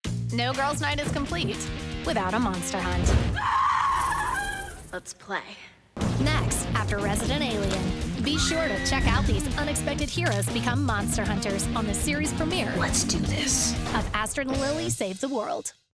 new york : voiceover : animation